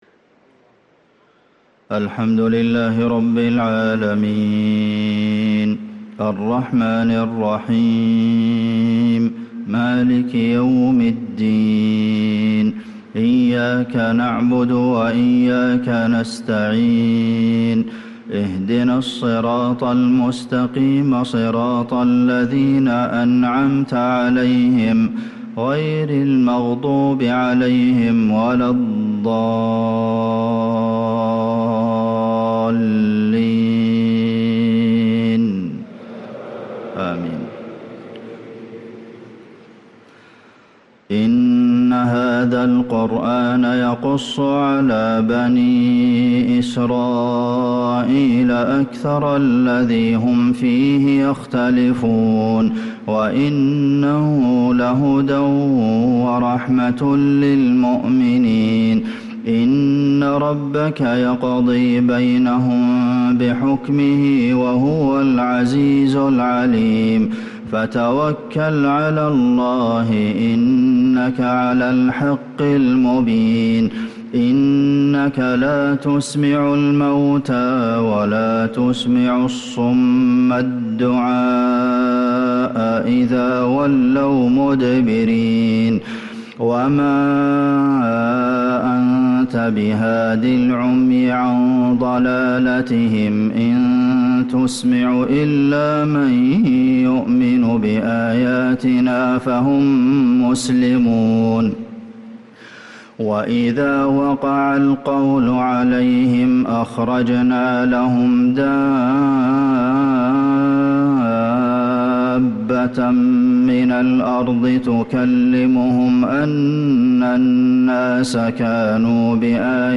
صلاة الفجر للقارئ عبدالمحسن القاسم 14 شعبان 1445 هـ
تِلَاوَات الْحَرَمَيْن .